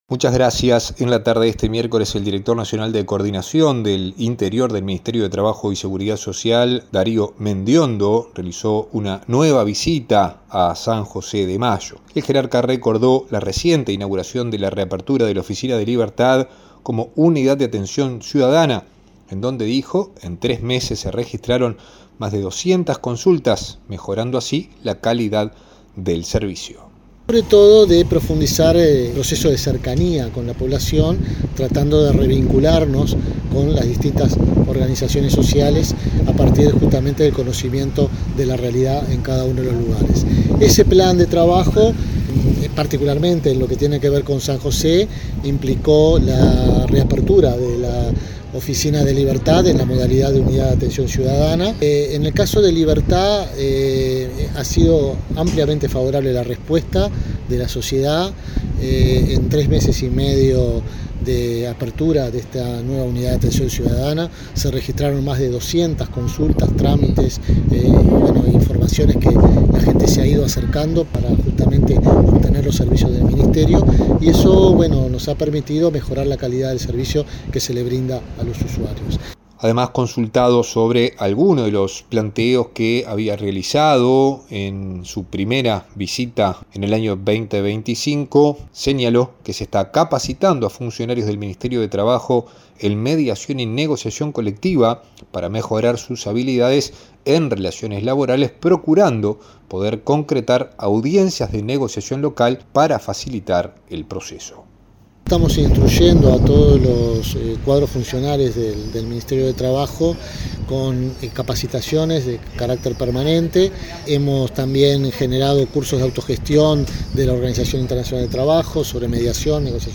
En rueda de prensa, valoró positivamente la reapertura de la oficina de Libertad como unidad de atención ciudadana y el incremento de consultas registradas en sus primeros meses de funcionamiento.